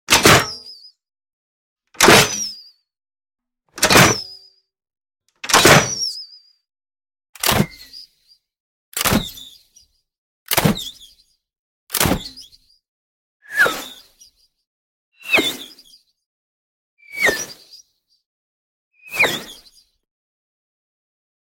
На этой странице вы найдете высококачественные записи звука выстрела из лука, свиста летящей стрелы и ее попадания в мишень.
Звук выстрела арбалетного механизма